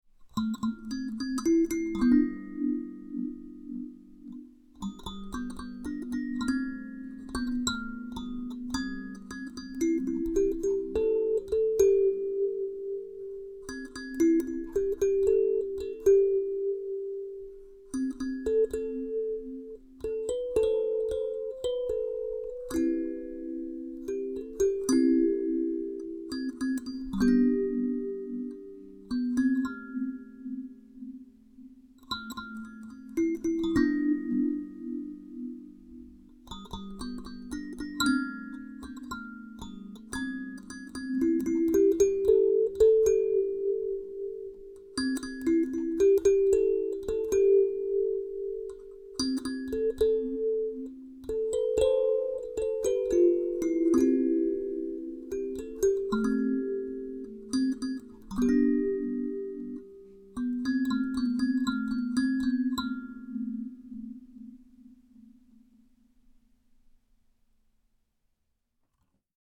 A Minor!